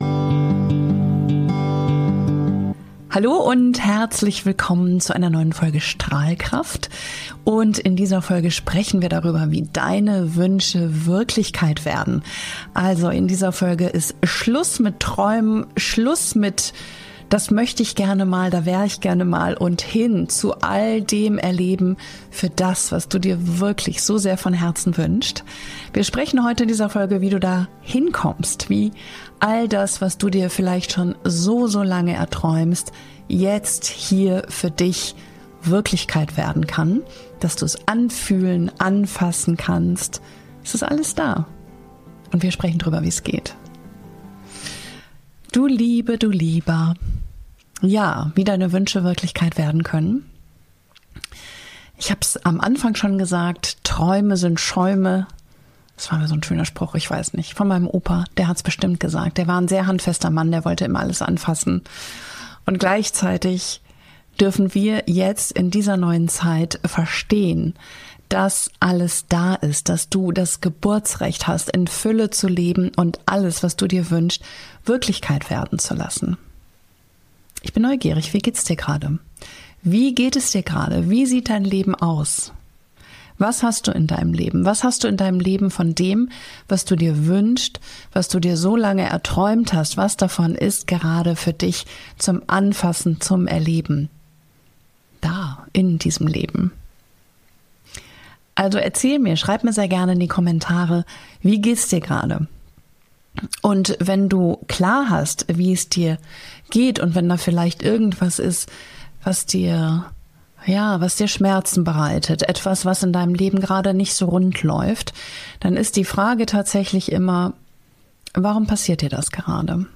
Geführte Meditation zu deinem zukünftigen Ich: Spüre jetzt, wie sich dein erfülltes Leben anfühlt — in Beruf, Liebe und persönlichem Wachstum. Für alle, die müde sind vom Warten.